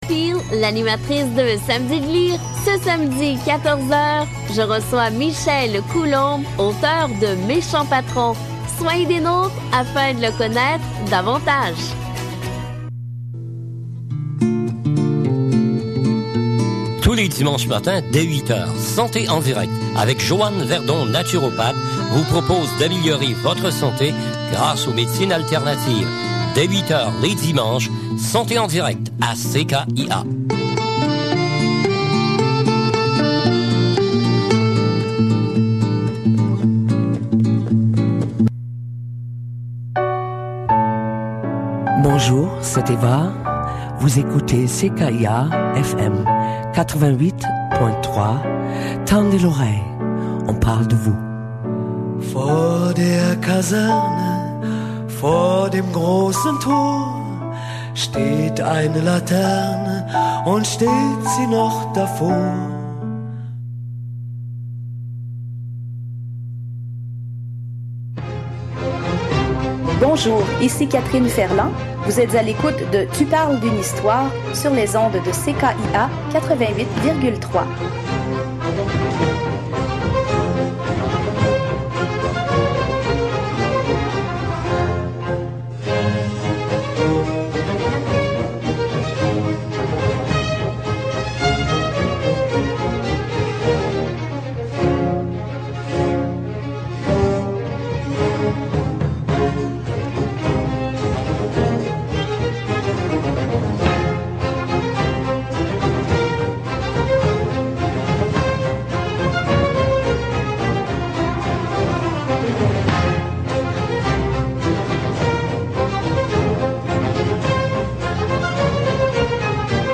nous entretient des diverses formes et fonctions du maloya en nous faisant découvrir quelques pièces typiques de ce style musical.